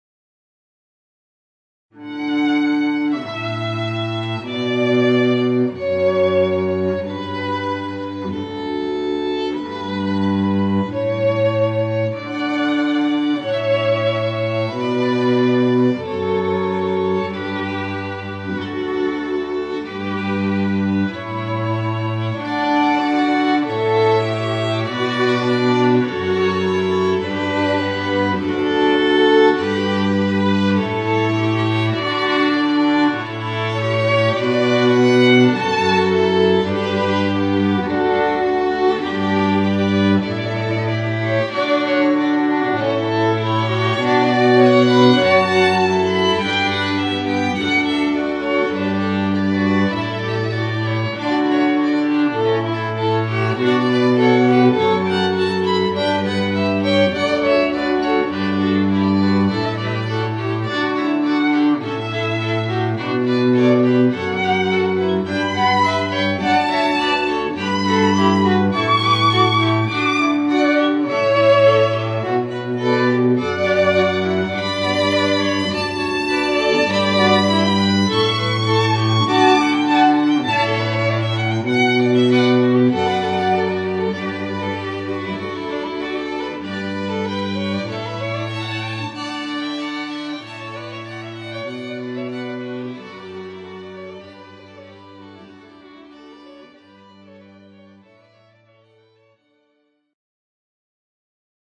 This Denver String Quartet can perform as a quartet or as a trio.
They perform music from the Baroque, Classical, and Romantic periods of music and arrangements of popular music, including Broadway shows and movie tunes, Celtic music, Renaissance music, Ragtime music, Tangos and Waltzes.